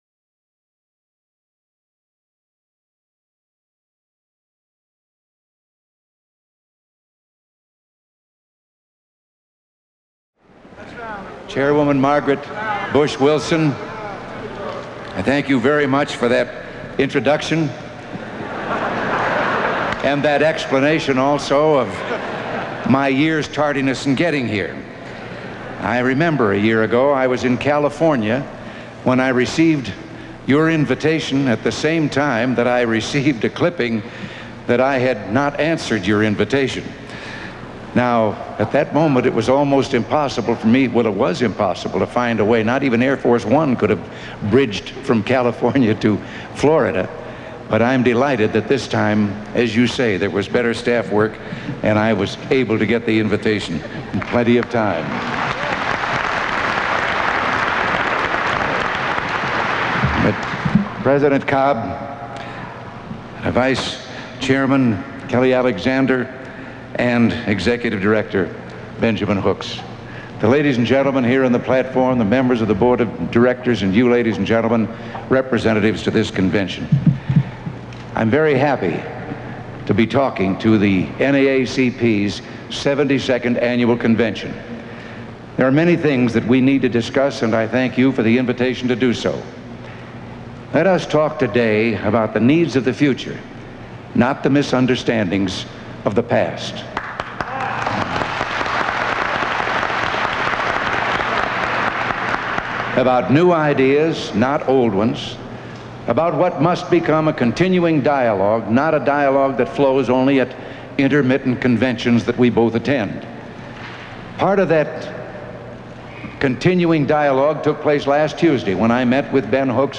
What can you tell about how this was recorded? June 29, 1981: Speech to the NAACP Annual Convention